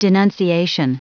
Prononciation du mot denunciation en anglais (fichier audio)
Prononciation du mot : denunciation
denunciation.wav